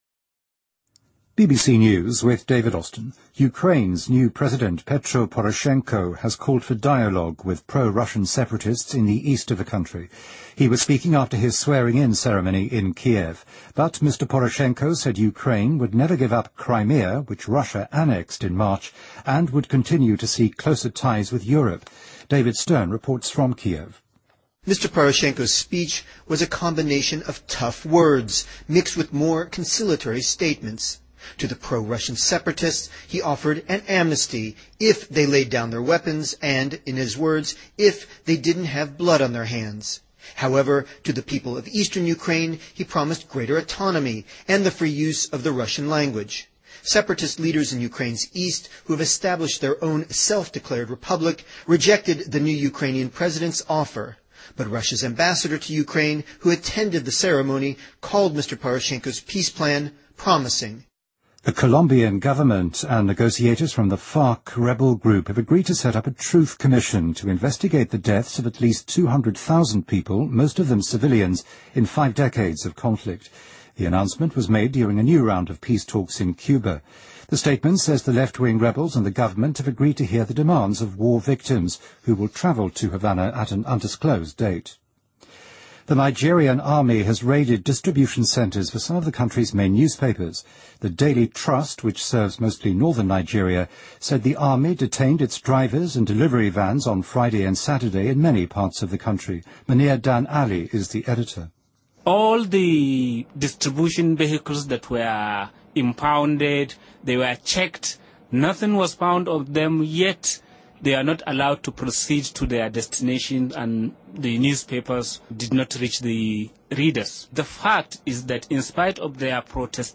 BBC news,乌克兰新总统波罗申科要求与该国东部的民间武装展开对话